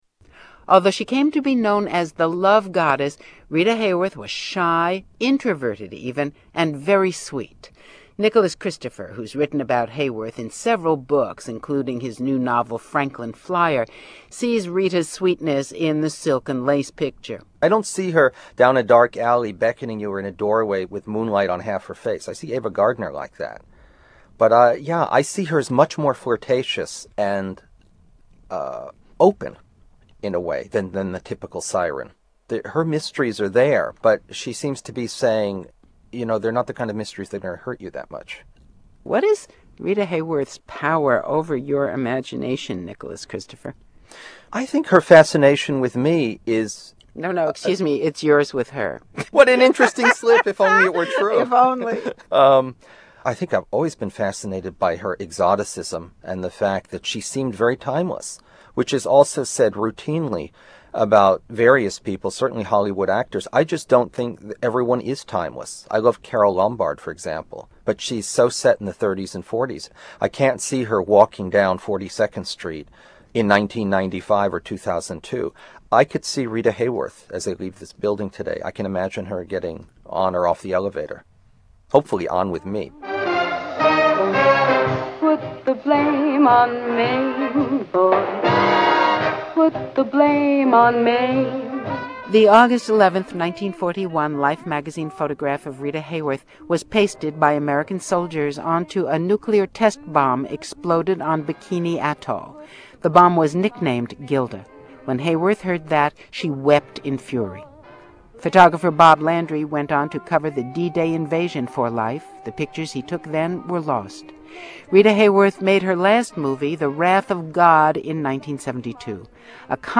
NPR report on Rita Hayworth 4